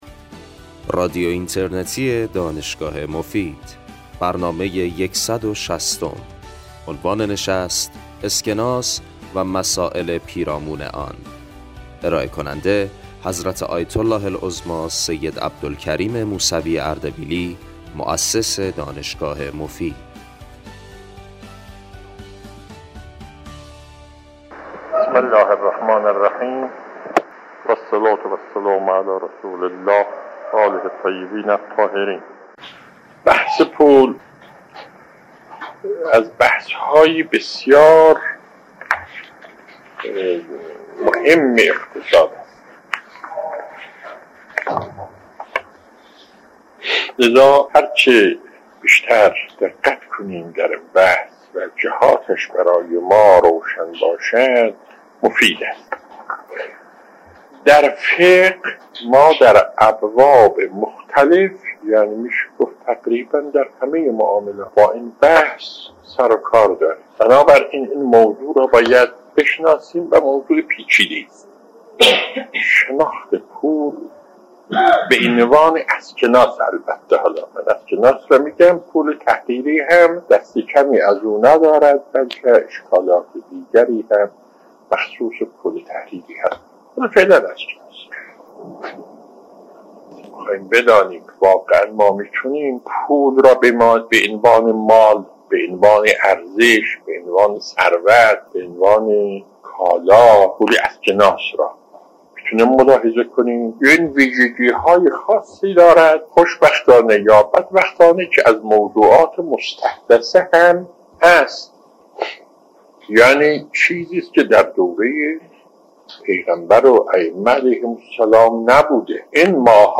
آیت الله العظمی موسوی اردبیلی در این سخنرانی که در اواسط دهه ۶۰ ایراد نموده اند، مسائل اقتصادیِ پیرامون پول کاغذی (اسکناس) از جمله مسئله ارزش، مقایسه اسکناس با دیگر وسیله‌های مبادله، پشتوانه واقعی، تورم، معایب چاپ پول، تاثیر نگرش دولت‌ها در سیاست‌های اقتصادی و ... را به نحو اجمالی به بحث می‌گذارند.